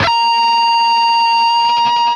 LEAD A#4 CUT.wav